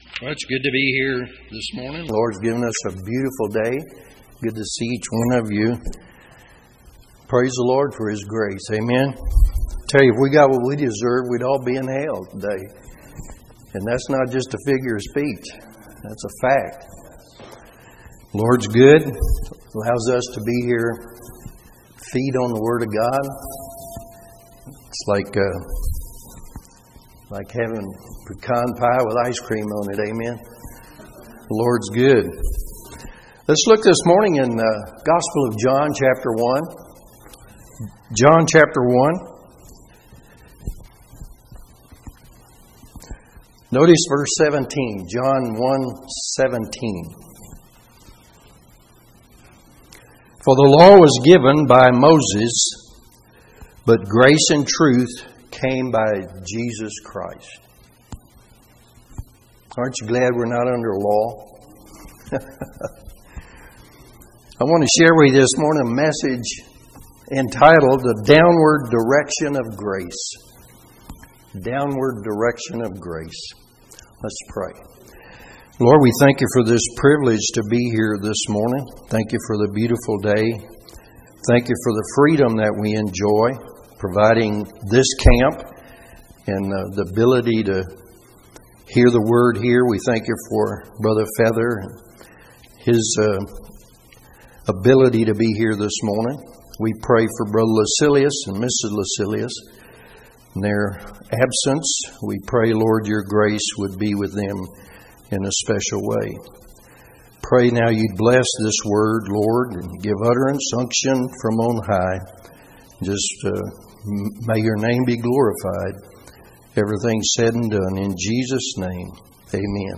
Session: Morning Devotion